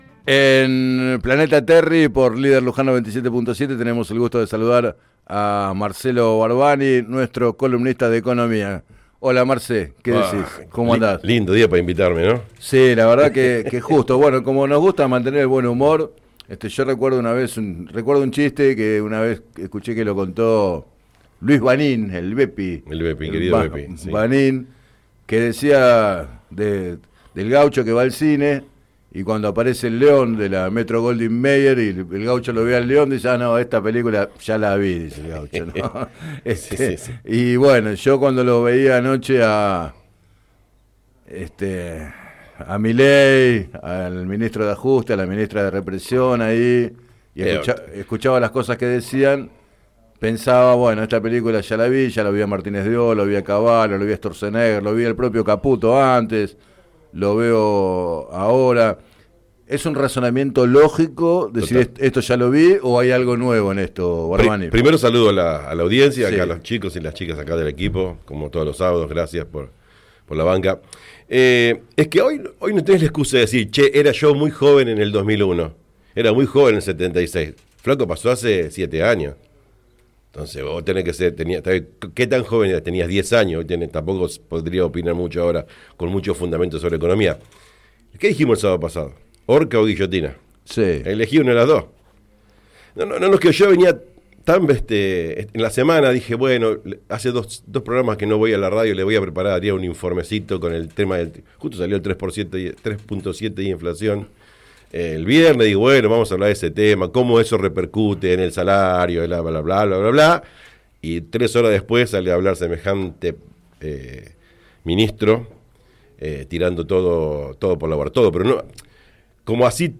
En su columna de Economía del programa Planeta Terri